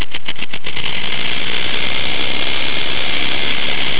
Hear a rattlesnake warning rattle
Rattlesnakes coil for protection, and shake their rattles as a warning, but they can strike only from a third to a half of their body length.
When frightened, the rattlesnake uses the muscles at the base of its tail to vibrate the rattle segments themselves together, making the rattling sound.
rattle.wav